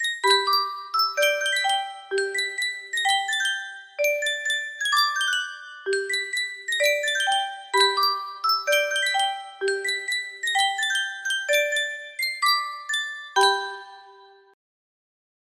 Sankyo Music Box - Down by the Riverside FNT
Full range 60